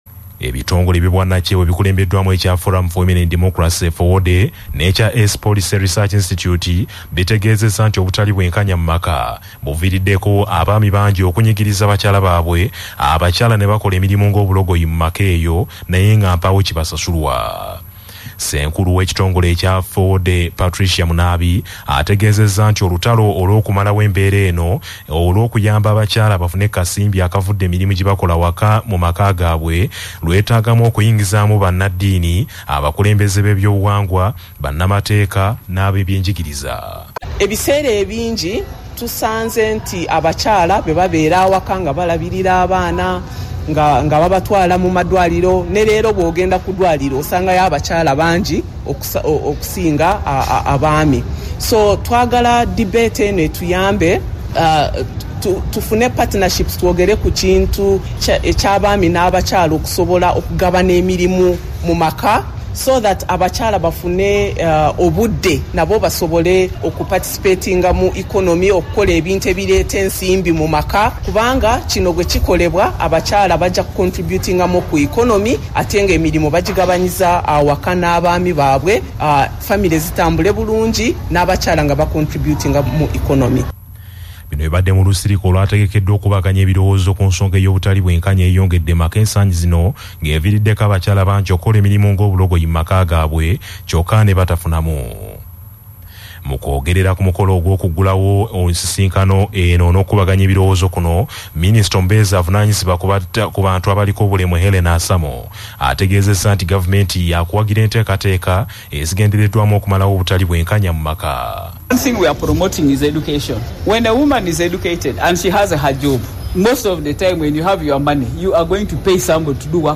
Audio News
Audio-News.mp3